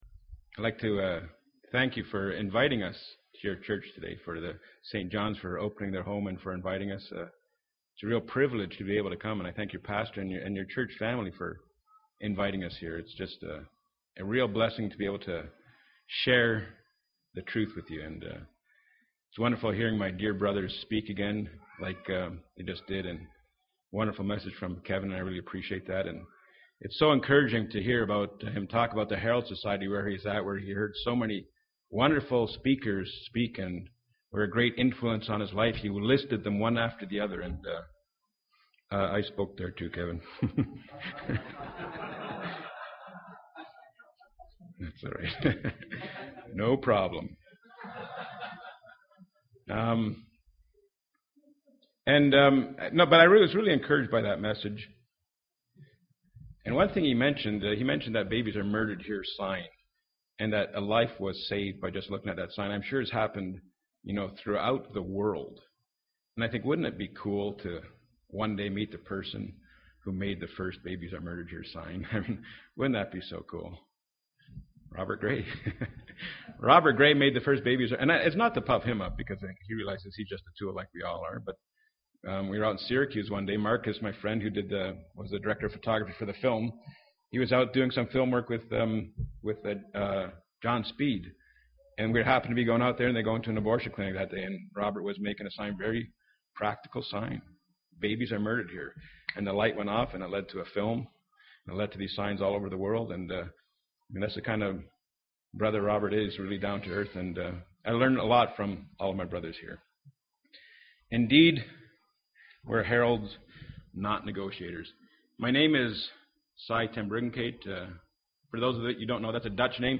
August 7, 2014 ( Conference )